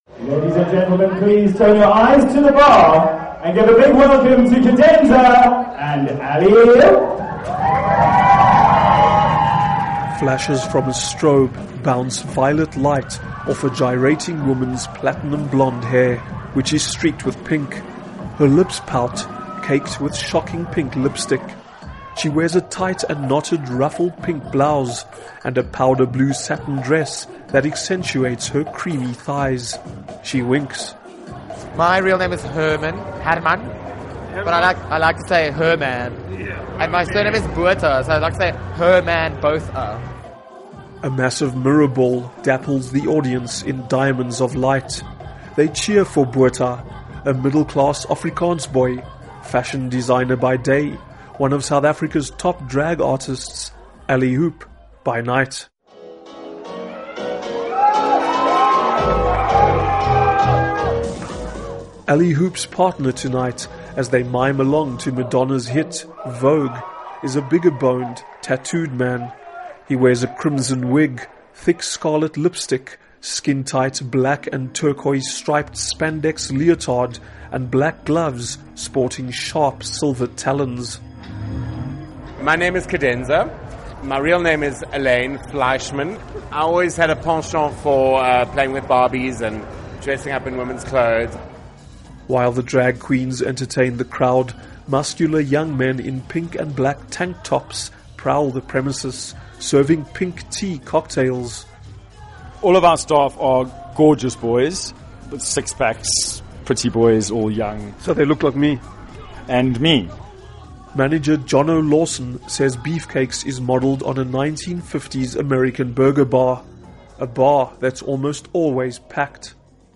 report on remarkable South African club